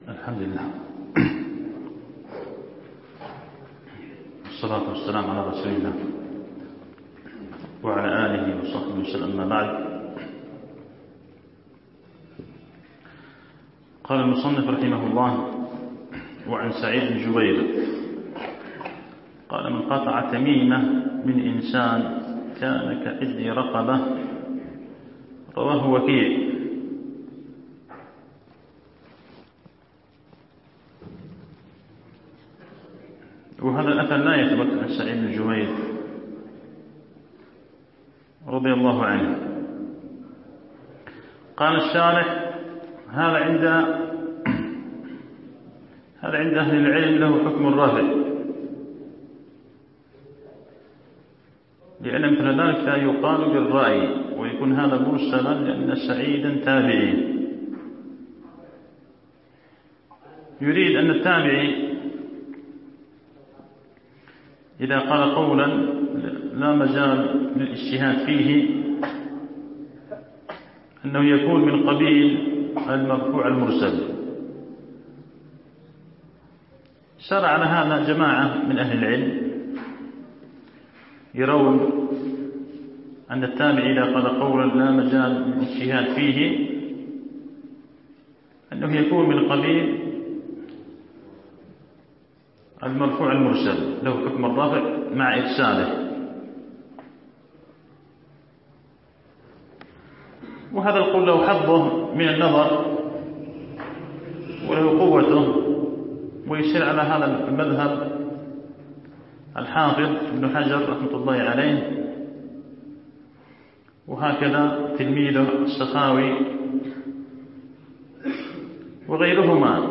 فتح المجيد الدرس 42.mp3